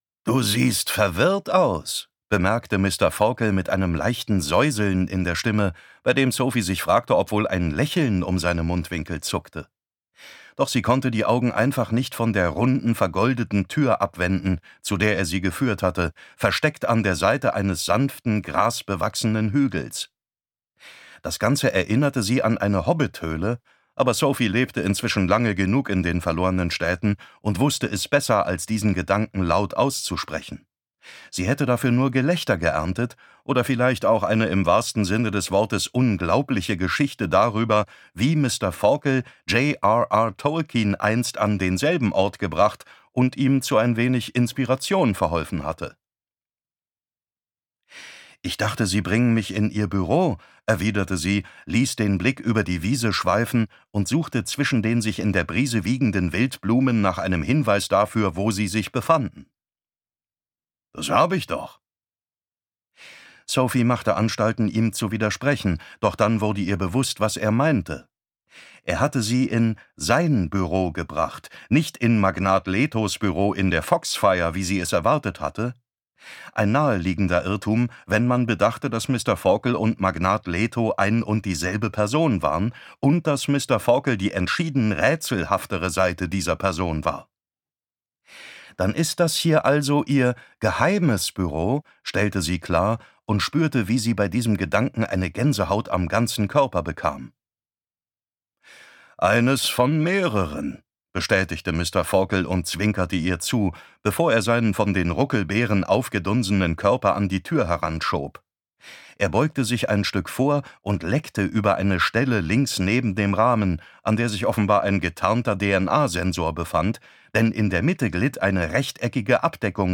David Nathan (Sprecher)